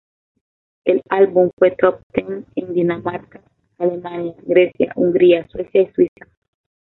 Sui‧za
/ˈswiθa/